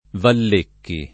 Vallecchi [ vall % kki ] cogn.